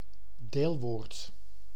Ääntäminen
Ääntäminen US : IPA : /pɑːr.tɪˌsɪ.pəl/ RP : IPA : /pɑːˈtɪsɪpəl/ Lyhenteet ja supistumat (kielioppi) part.